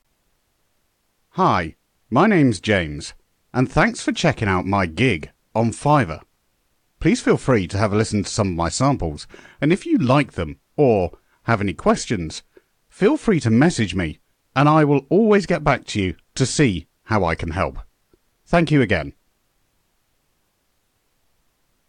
外籍英式英语